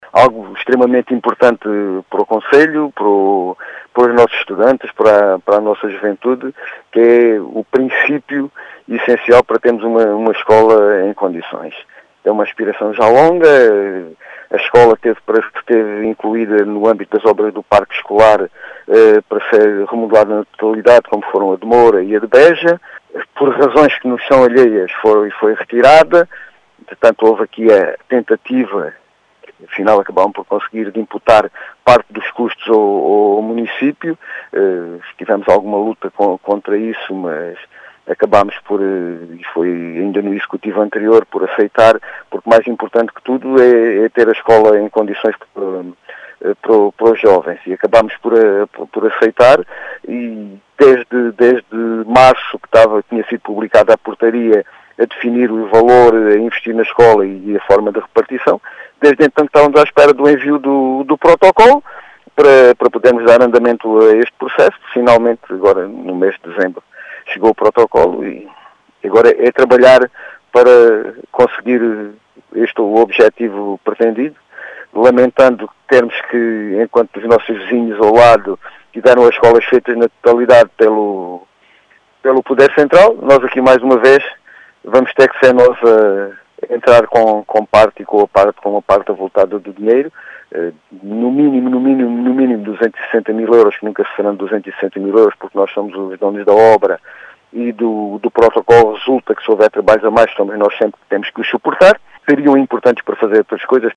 As explicações são de João Efigénio Palma, presidente da Câmara Municipal de Serpa que lamenta os custos que foram atribuídos à autarquia.